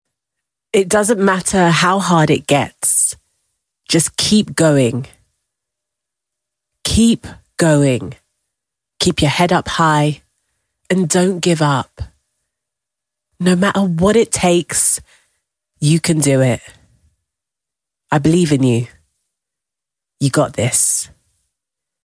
Natural London Accent
London English, British English, Nigerian English, African English
Young Adult
Natural London MP3.mp3